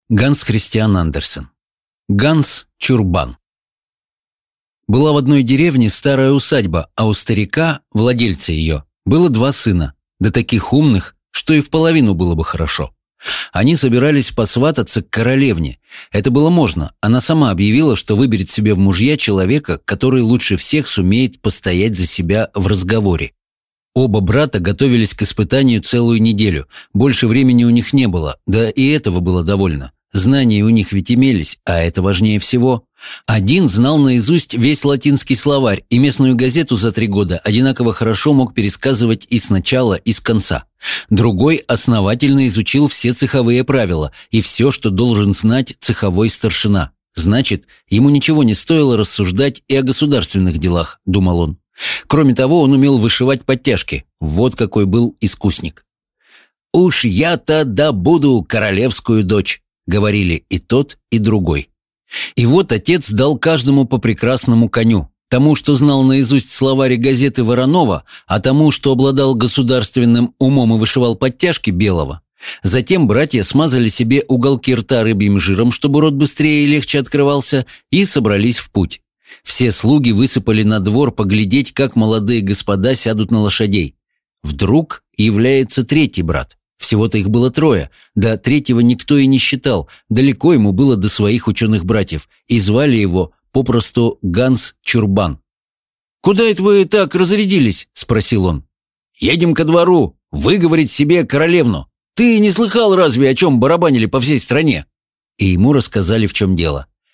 Аудиокнига Ганс Чурбан | Библиотека аудиокниг
Прослушать и бесплатно скачать фрагмент аудиокниги